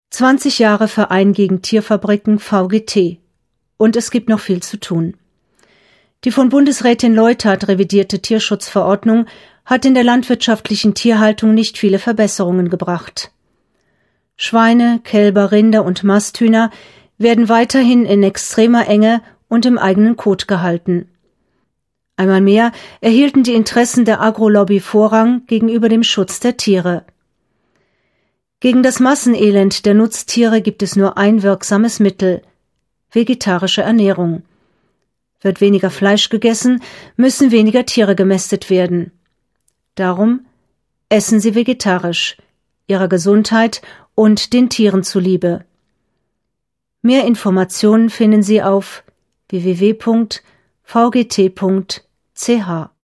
Radio-Spot zum 20-jährigen Jubiläum des VgT